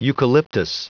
Prononciation audio / Fichier audio de EUCALYPTUS en anglais
Prononciation du mot : eucalyptus